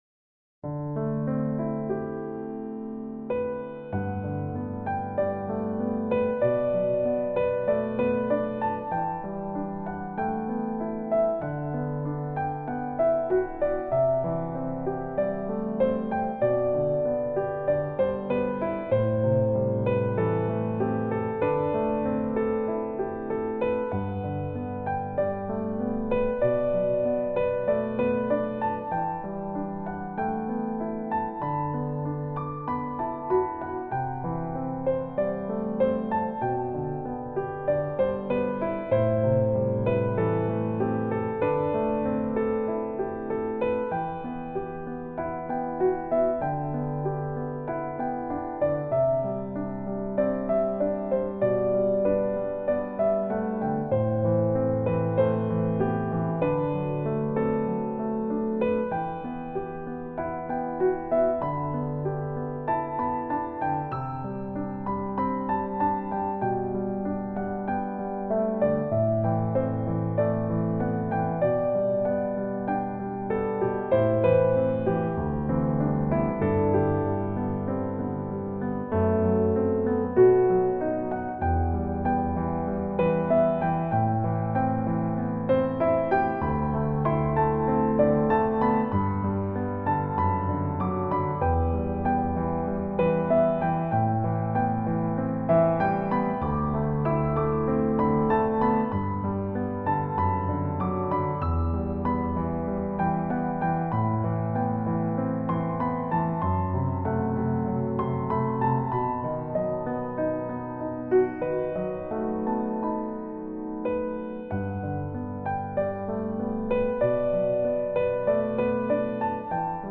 Instrumentation : Piano
Genre:  Romantique